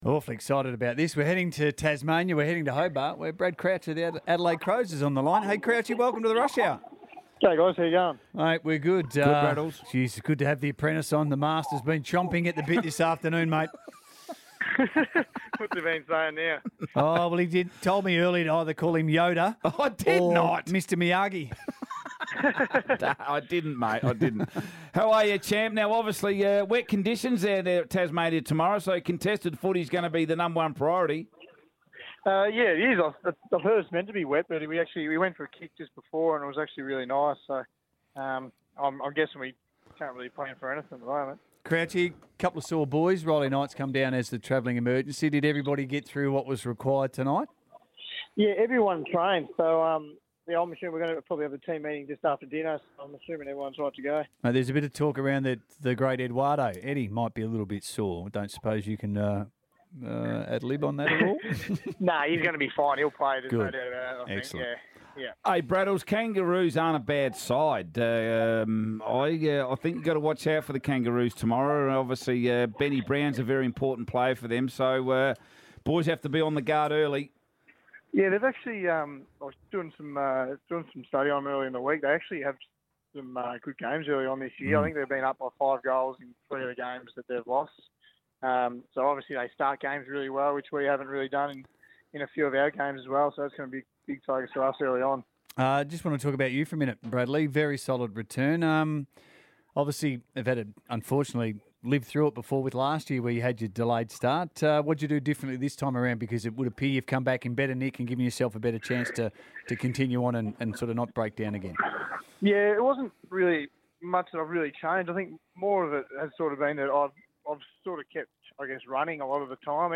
Crows midfielder Brad Crouch on Triple M's The Rush Hour